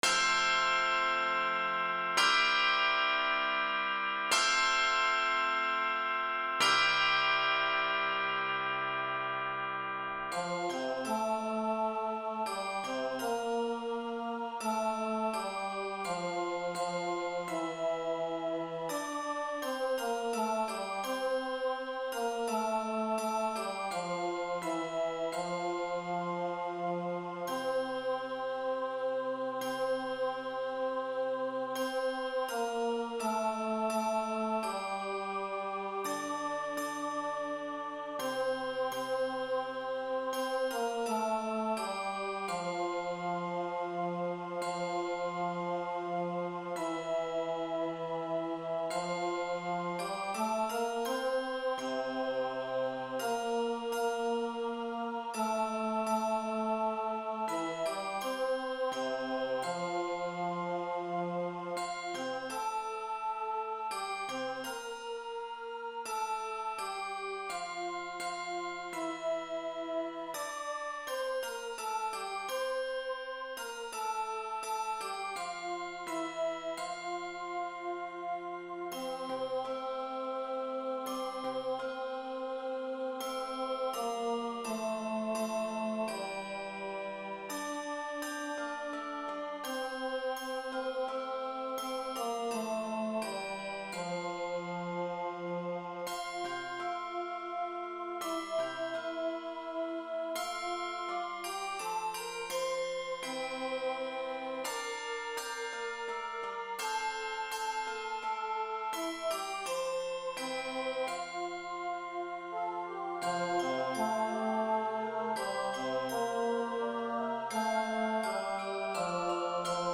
this medley is set in F Major